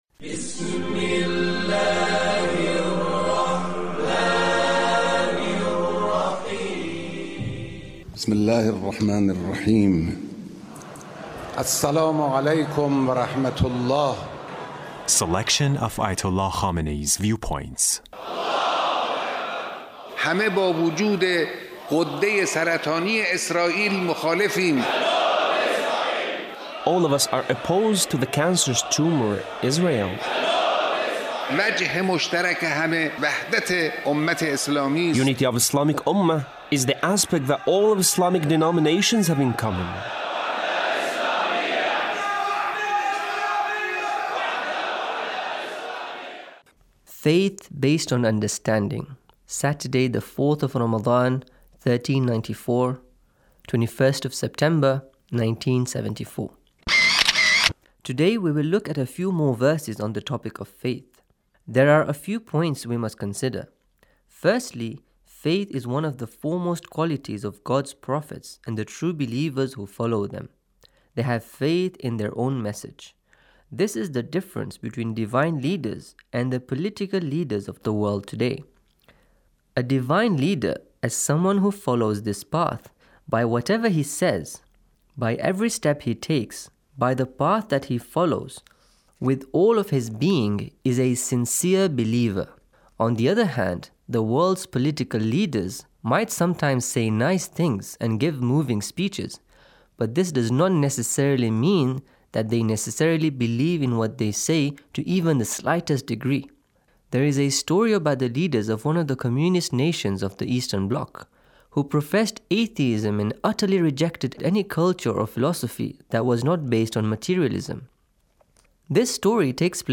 Leader's Speech